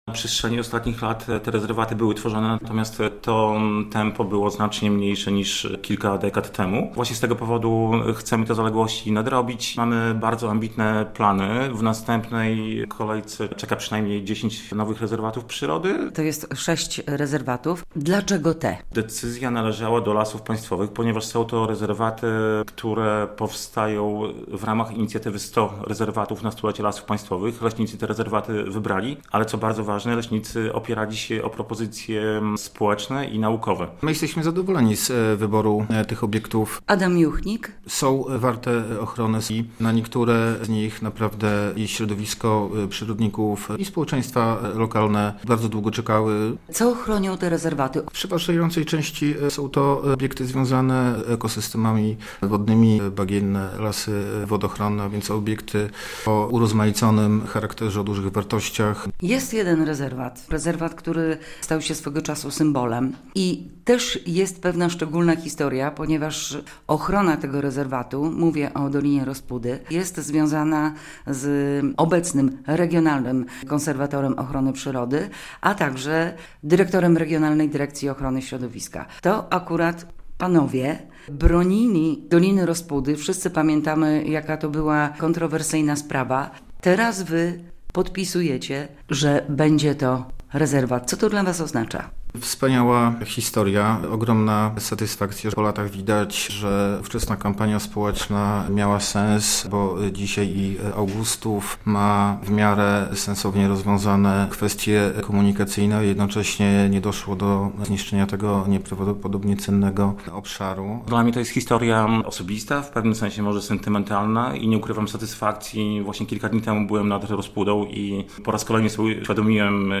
Z dyrektorem RDOŚ w Białymstoku Adamem Juchnikiem i RKOP Regionalnym Konserwatorem Ochrony Przyrody Adamem Bohdanem rozmawia